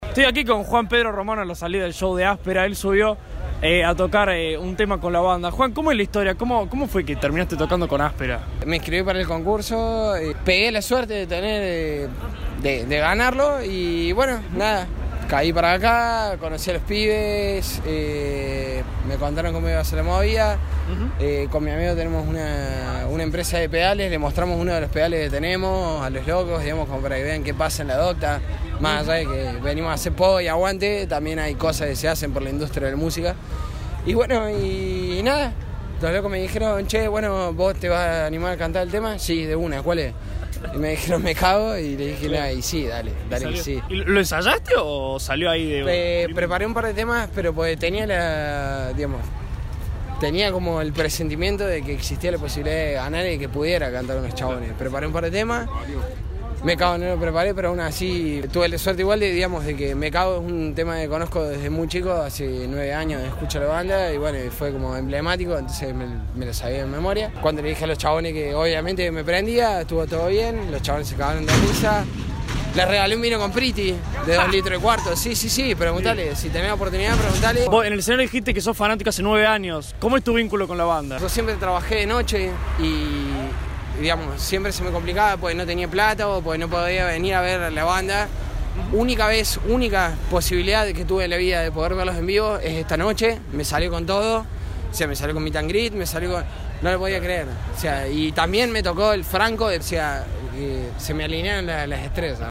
Entrevista exclusiva para De la Vieja Escuela.